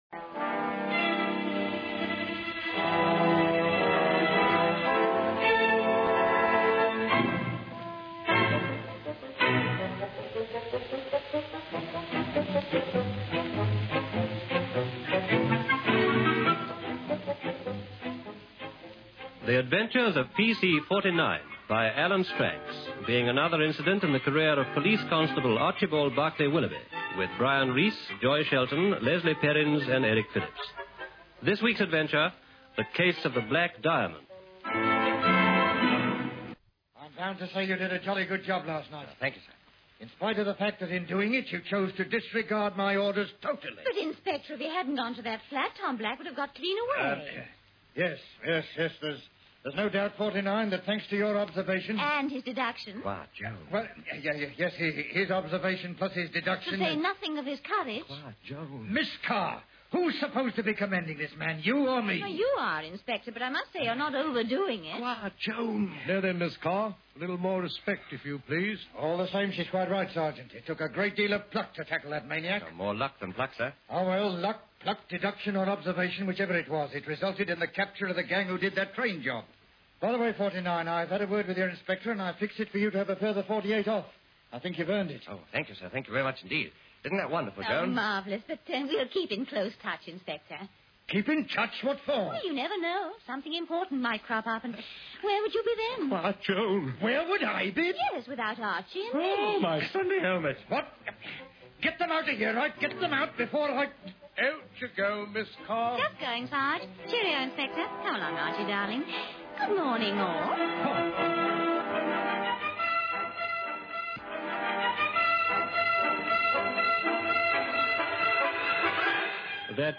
Brian Reece as PC 49 & Joy Shelton as Joan